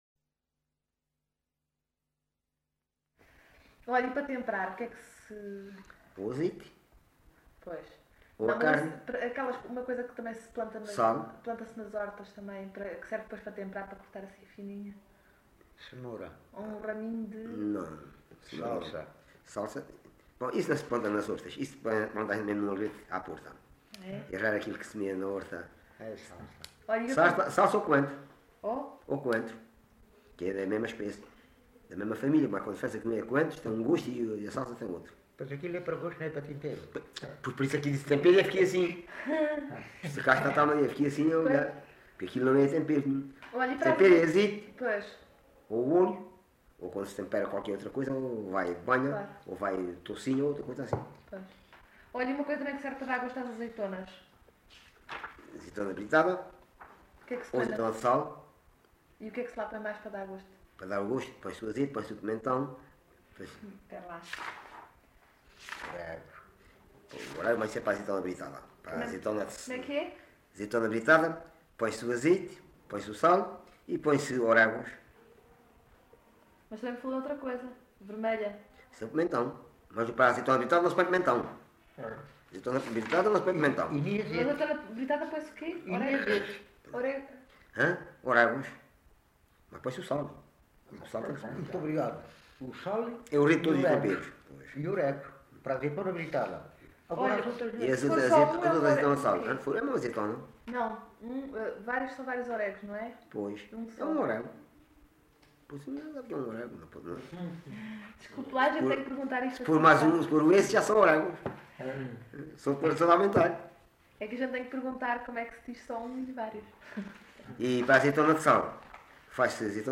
Alte, excerto 26
LocalidadeAlte (Loulé, Faro)